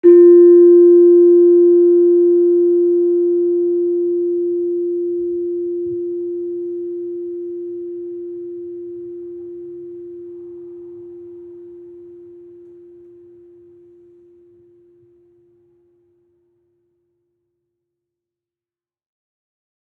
Gender-2-F3-f.wav